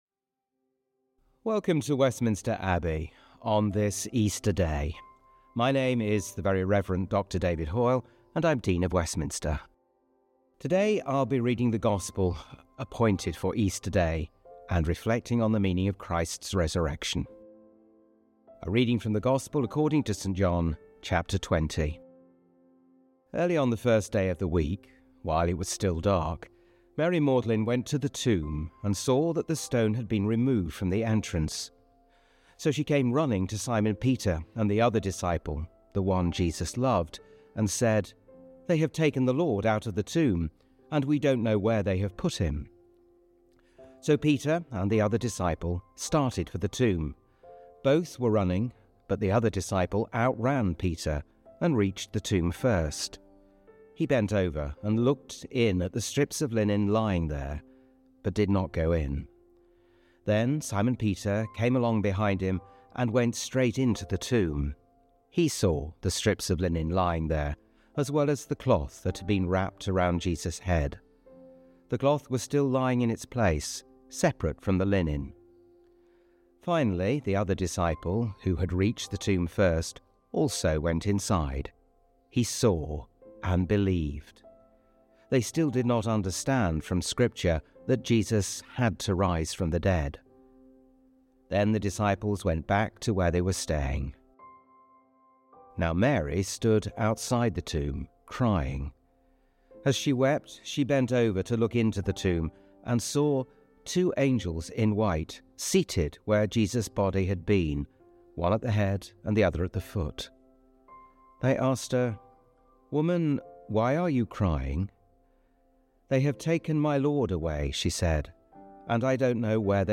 The Very Reverend Dr David Hoyle, Dean of Westminster, reflects on the meaning of Easter, and how Jesus Christ's resurrection shows us what it means to be perfectly human.